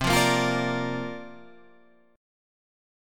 C9sus4 chord